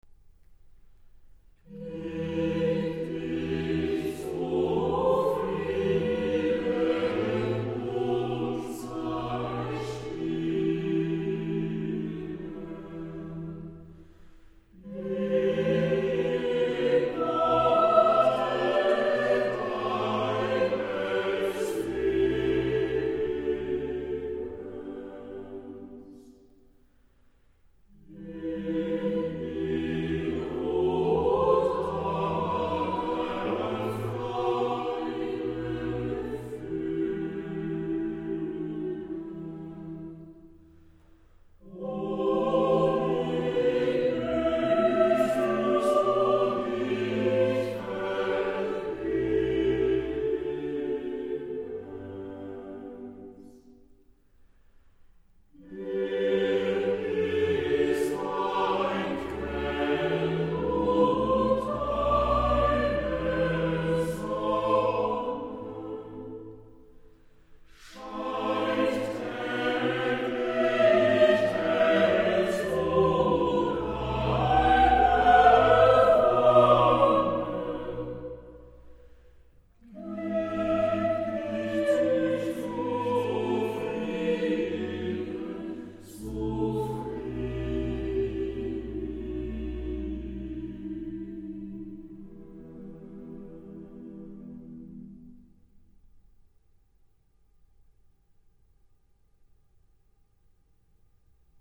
14-vierstimmige-chorgesc3a4nge-gieb-dich-zufrieden-und-sei-s.mp3